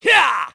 Roman-Vox_Attack4_kr.wav